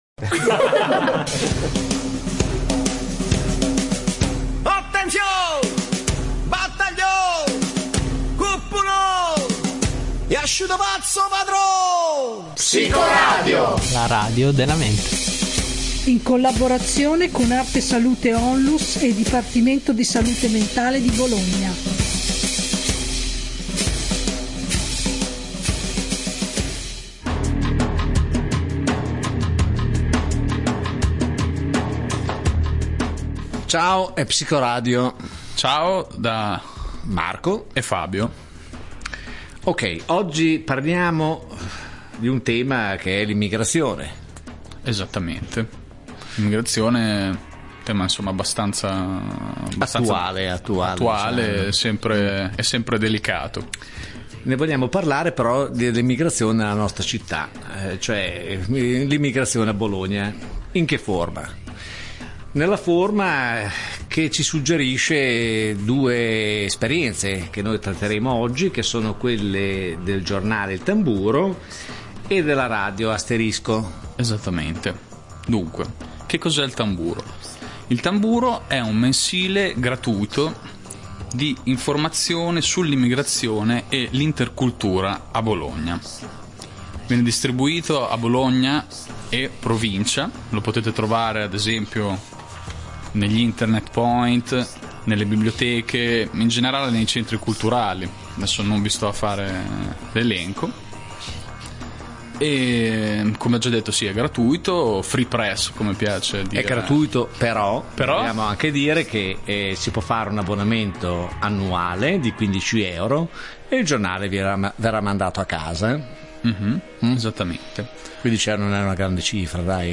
Musica e parole degli immigrati a Bologna
Questa volta la redazione spazia da proverbi a musiche che ci arrivano da altri mondi, estratti di radio Asterisco, come sempre in ascolto di “altre” voci.